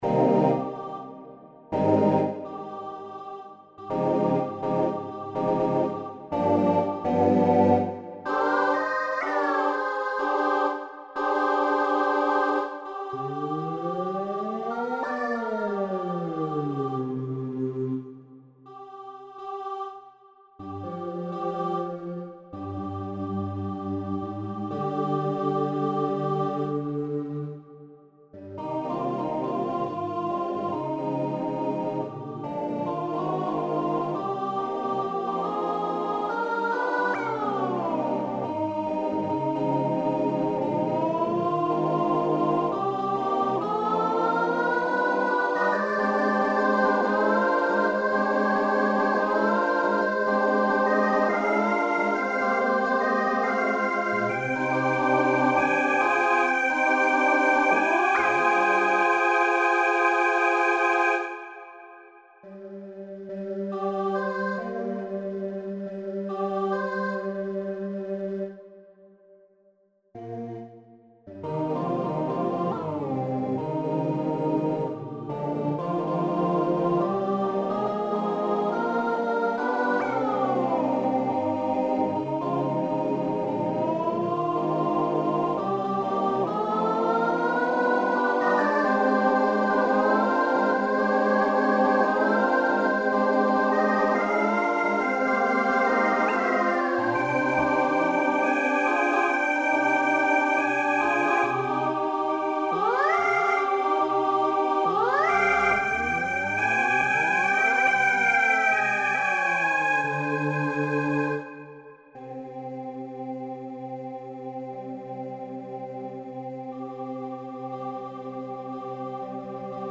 SSAATTBB